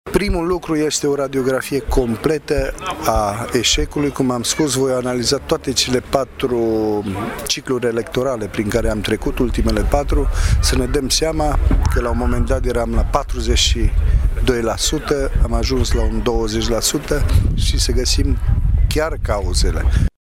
Primul pas al noii strategii va fi radiografierea eșecului de la ultimele alegeri, a mai spus Cristian Chirteș: